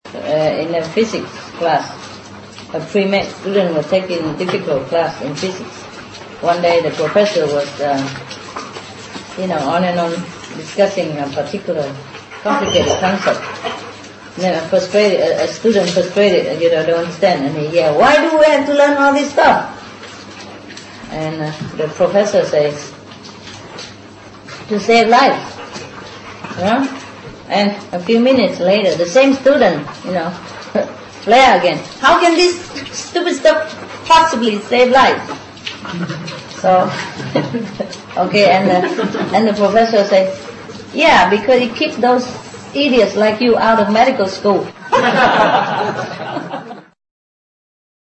Master Tells Jokes
Spoken by Supreme Master Ching Hai, February 3, 2003,